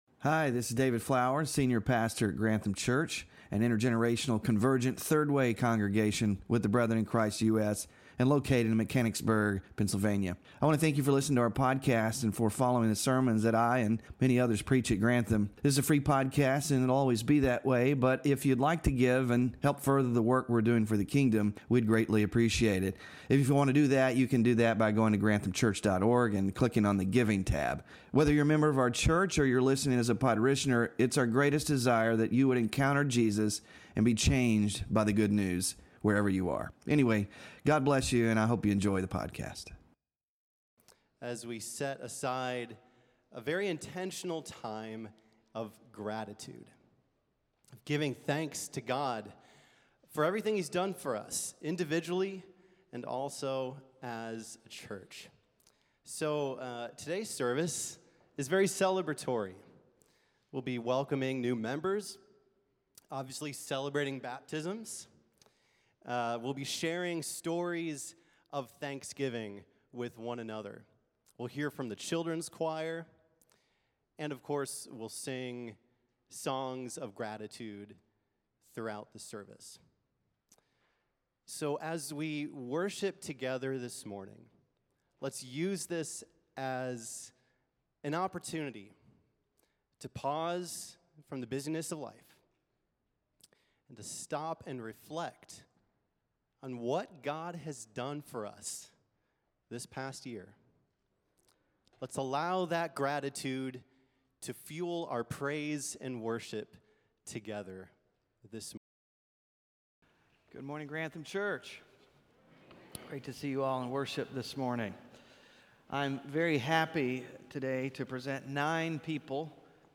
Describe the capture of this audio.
Thanksgiving Service